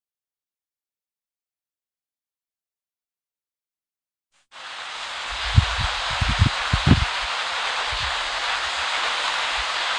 Начинается вьюга:
snowstorm3.wav